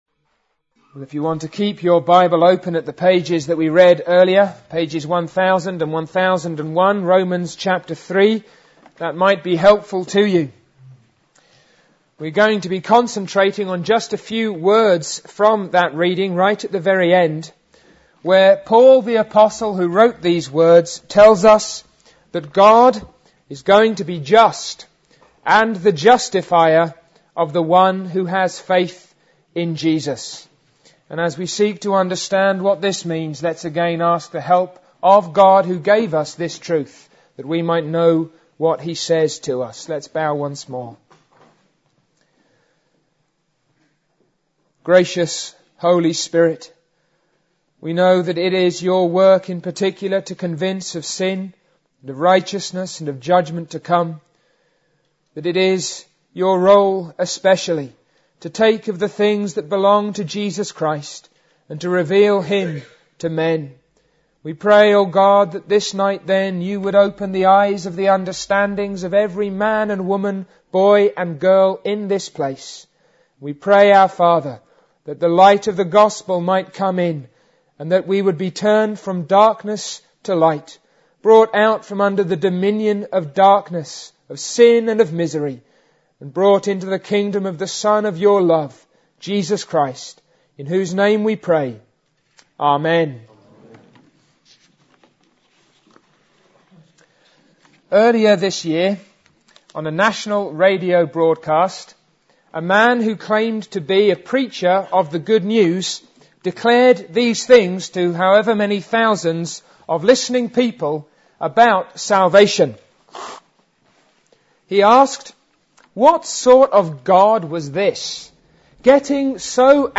APC - Sermons